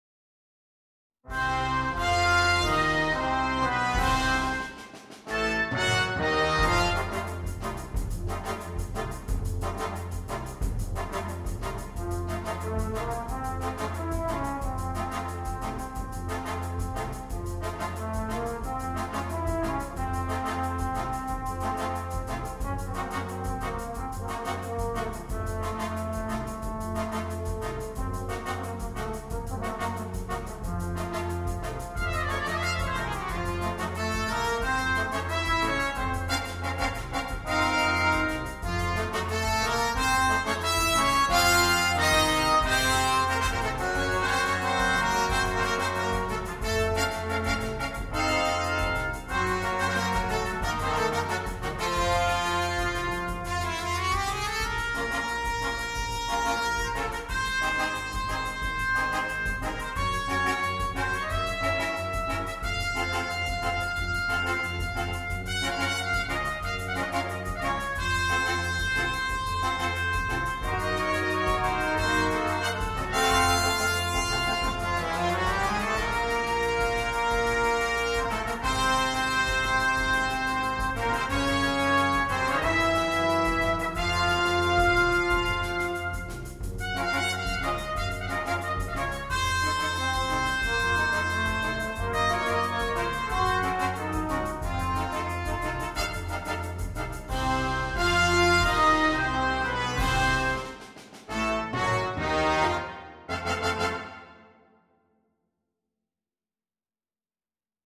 для брасс-бэнда.